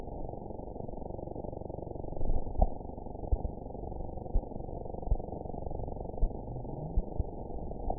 event 922711 date 03/17/25 time 20:02:02 GMT (1 month, 2 weeks ago) score 6.06 location TSS-AB03 detected by nrw target species NRW annotations +NRW Spectrogram: Frequency (kHz) vs. Time (s) audio not available .wav